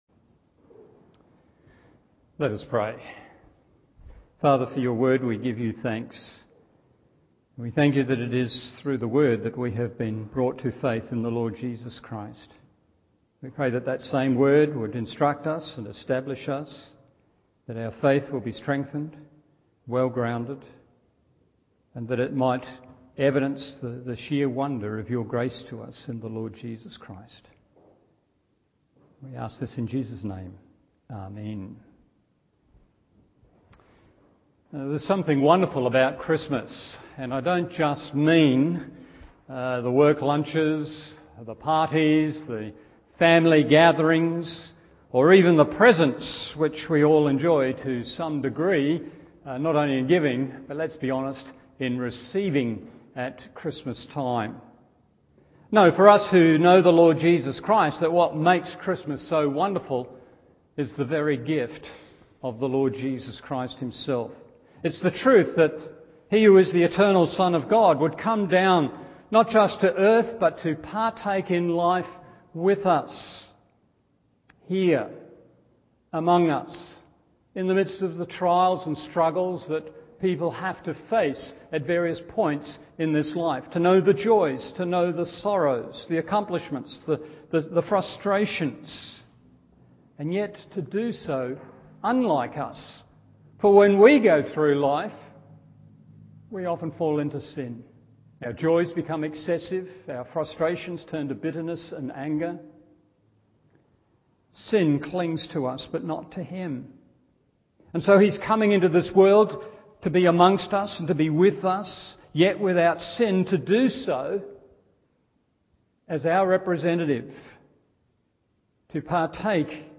Evening Service Hebrews 10:10-22 1. What is true concerning Christ 2. What is true concerning the believer 3. What is true concerning our relationship to God…